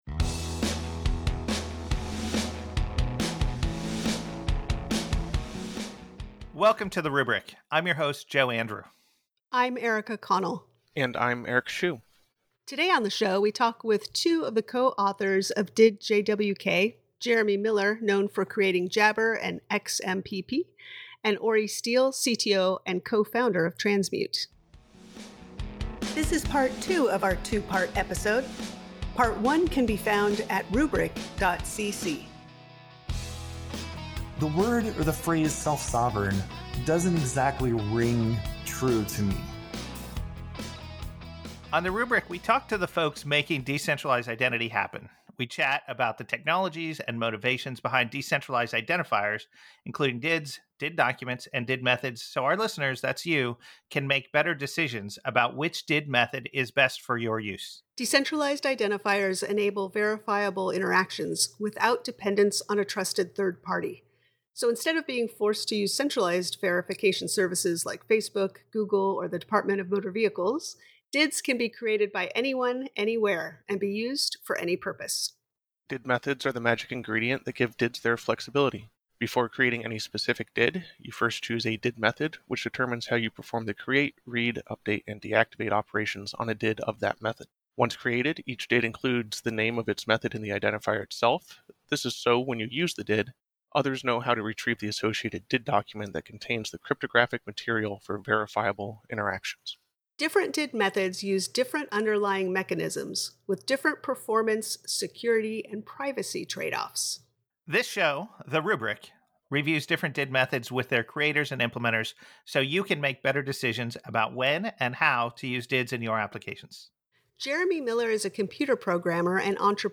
We talk with two of the co-authors of did:jwk